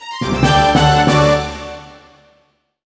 combat_win.wav